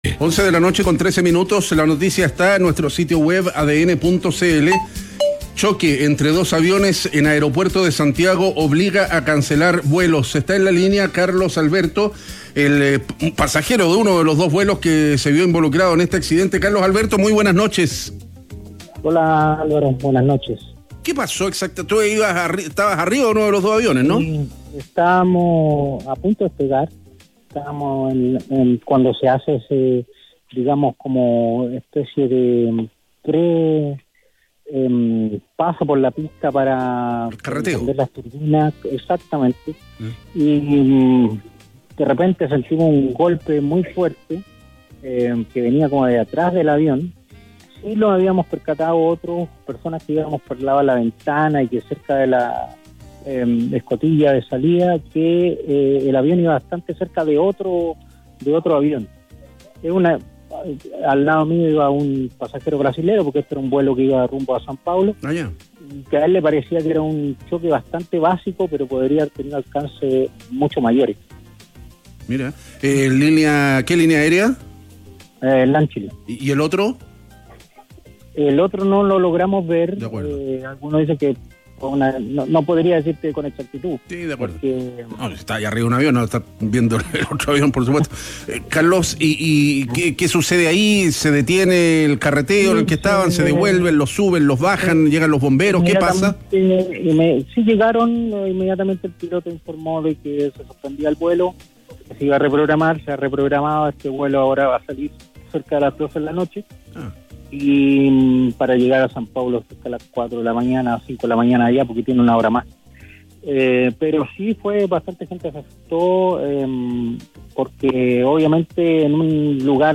pasajero narra el inédito choque entre dos aviones en aeropuerto de Santiago